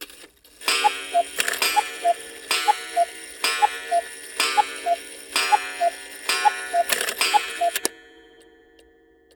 cuckoo-clock-08.wav